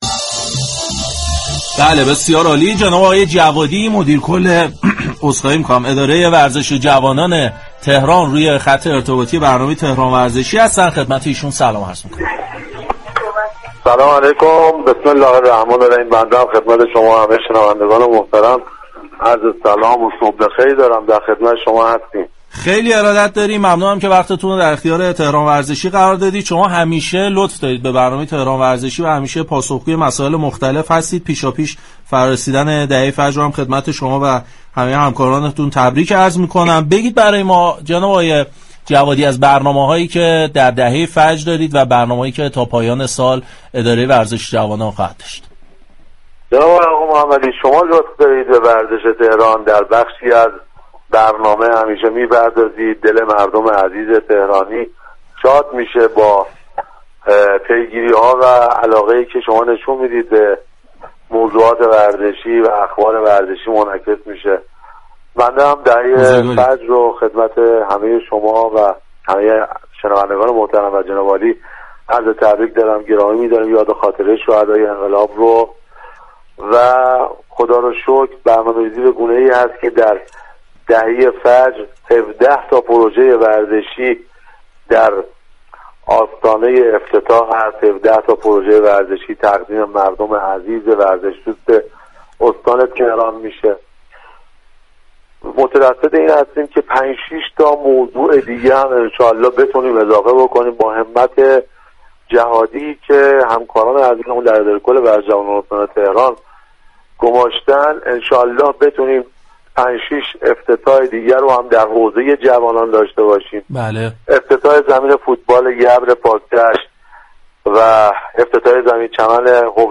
به گزارش پایگاه اطلاع رسانی رادیو تهران، علی جوادی مدیركل ورزش و جوانان استان تهران در گفت و گو با «تهران ورزشی» اظهار داشت: همزمان با ایام دهه فجر 17 پروژه ورزشی در استان تهران افتتاح می‌شود.